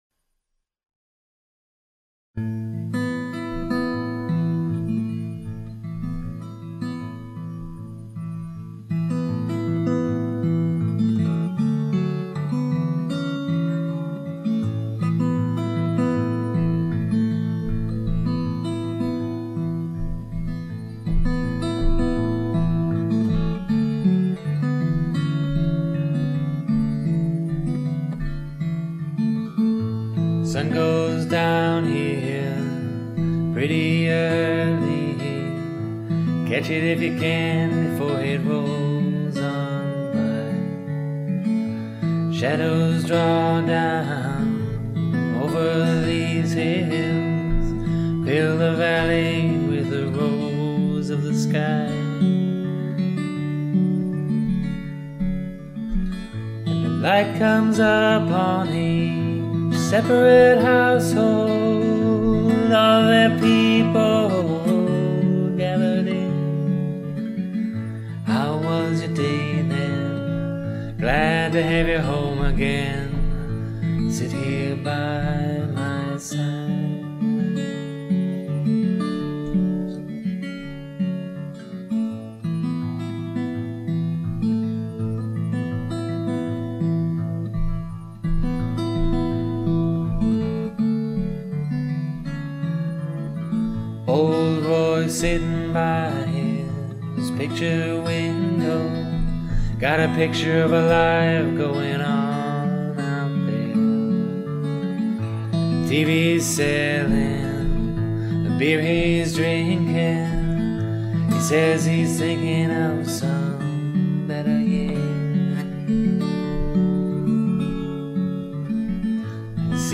(Should you post a scratch track? Well, I do…)